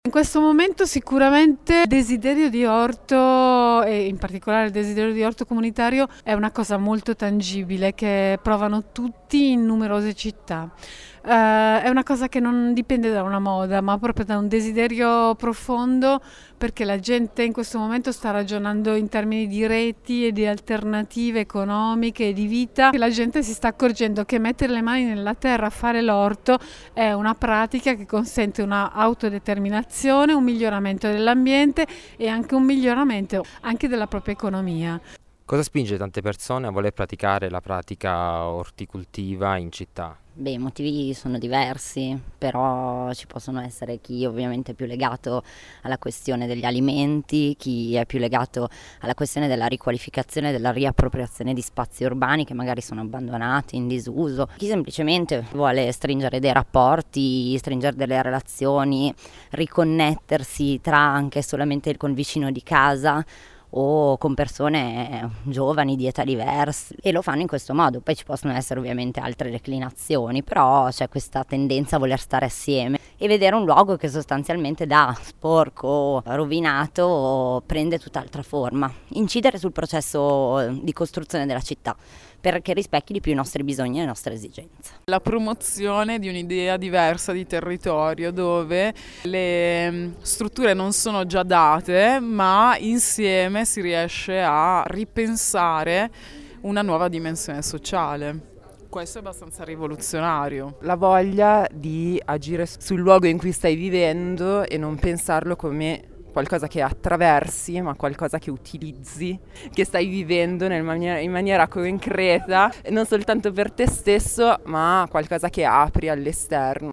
Ascolta le voci di alcuni partecipanti raccolte durante la tavola rotonda di sabato pomeriggio al centro Guarnelli (foto su):